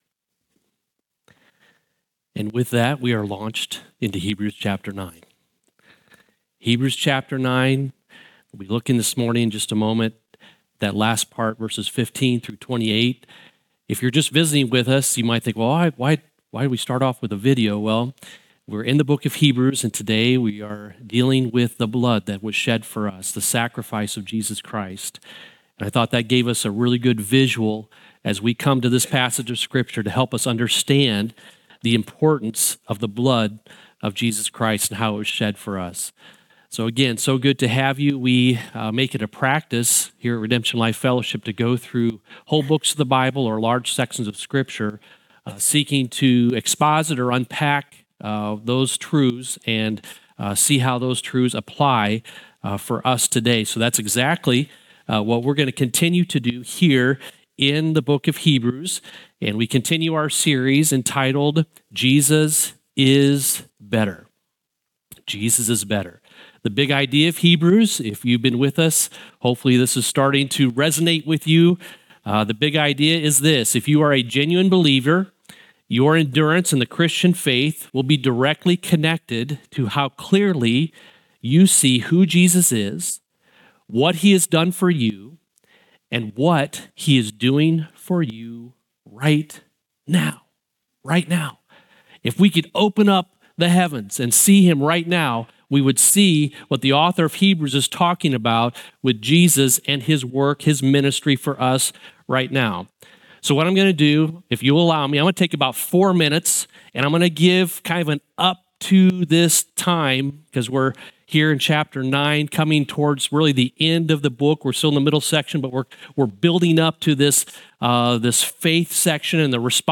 Summary of the Sermon